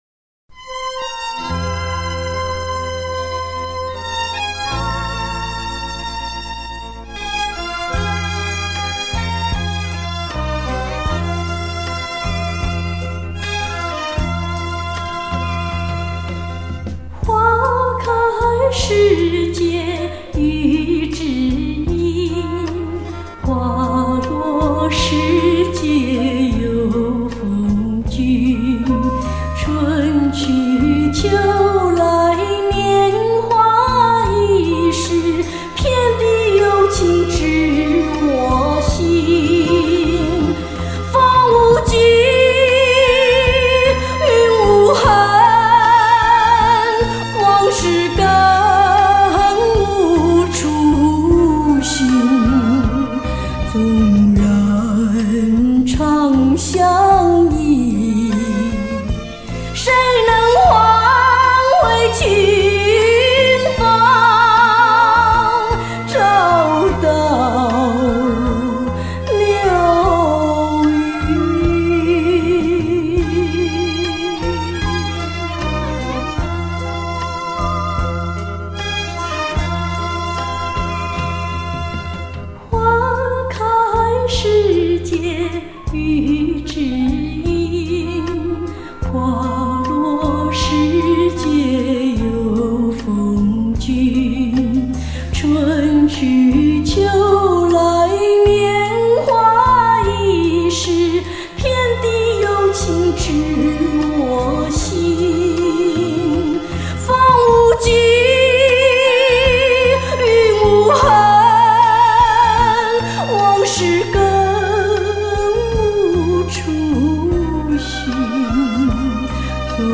专辑类别：录音室专辑